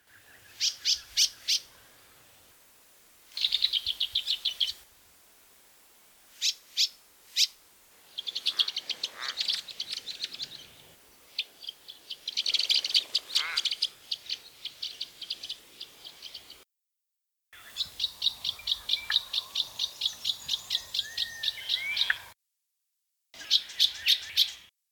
Brown-headed Honeyeaters
By no means spectacular in plumage and often overlooked, it is nonetheless an endearing small bird with its constant activity and distinctive chattering contact call and staccato song – click on the bar below to hear some of its vocalisations.
Adds a bit of variety – also noticed a frog and currawong got in on the act.
brown-headed-honeyeater.mp3